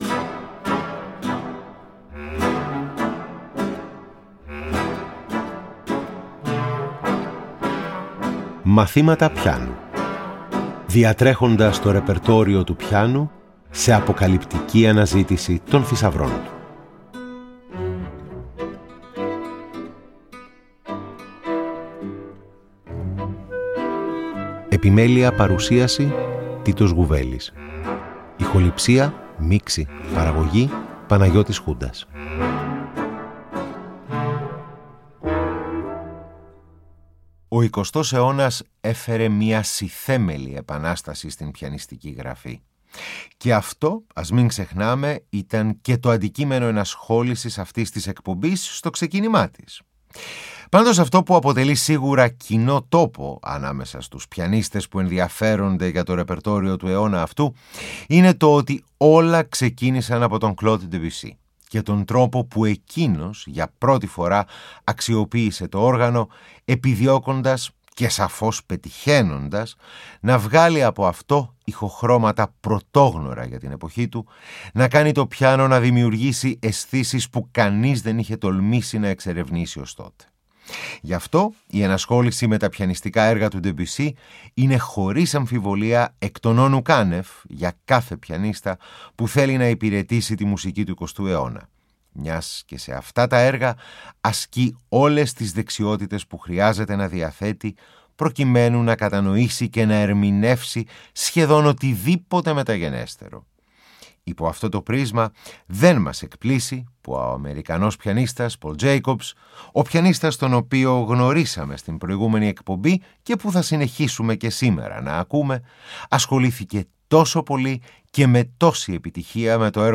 Εργα για Πιανο Σπουδες για Πιανο